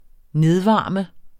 Udtale [ -ˌvɑˀmə ]